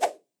quick transitions (2).wav